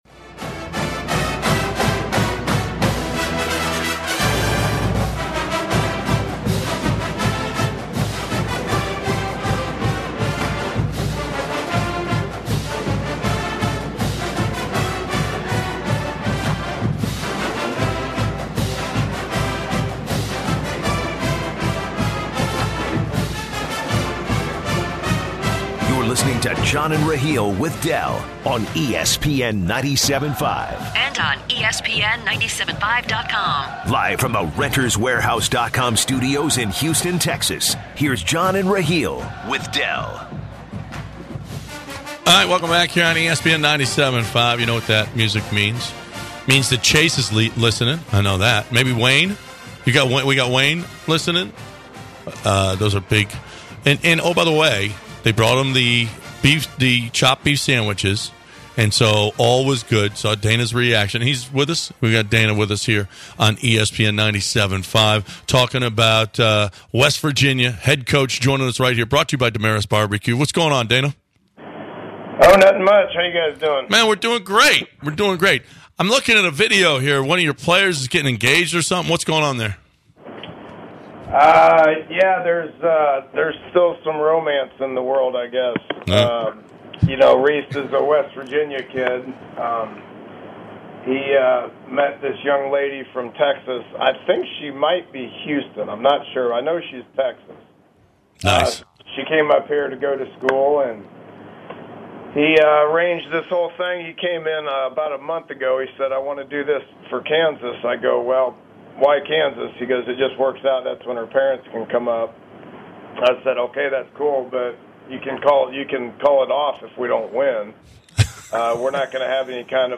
West Virginia head coach Dana Holgorsen calls in for a quick chat.